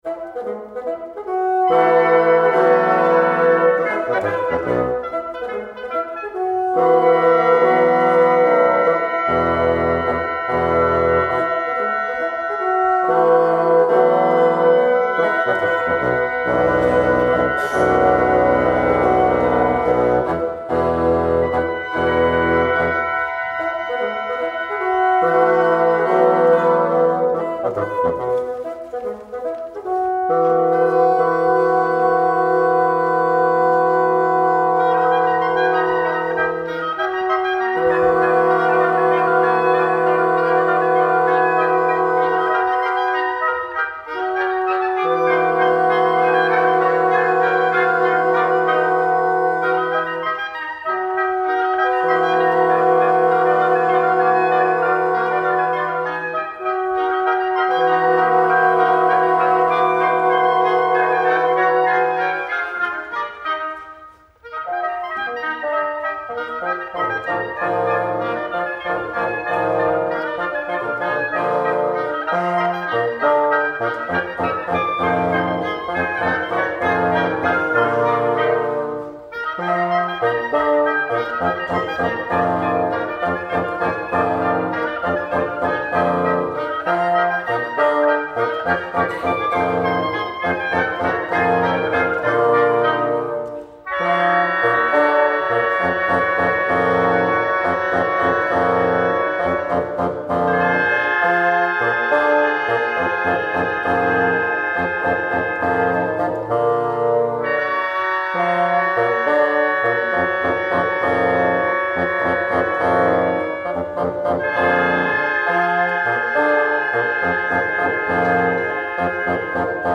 The idea of all of these pieces is that they all appear completely innocent, until they suddenly get stuck on a particularly dodgy cadence.
Here's another example, from The Society for Classical and Authentic Music, comprising on this occasion five oboes and five bassoons, who perform three of the Inanities, Jagdmazurka, Teufelwalzer and Stupid Tune, with overlaps between the bassoon and oboe versions, before being requested by the conductor to efface themselves;